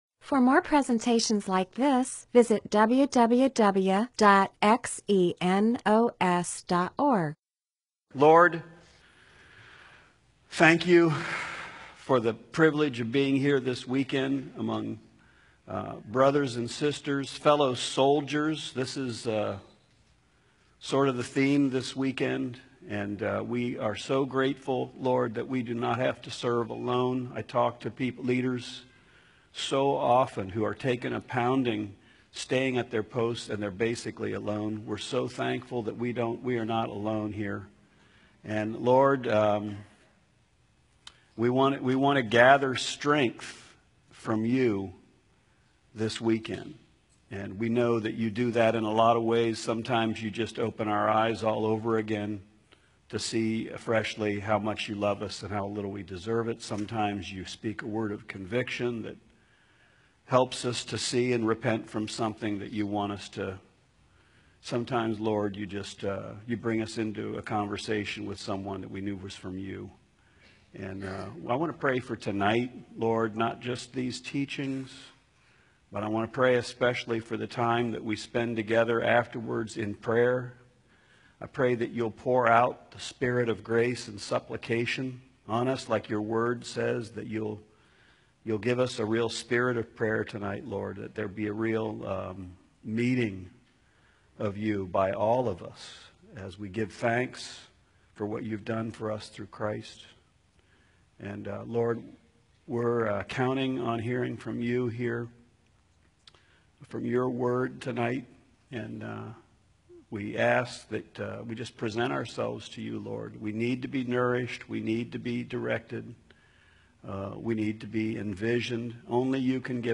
MP4/M4A audio recording of a Bible teaching/sermon/presentation about 1 Peter 4:7-8.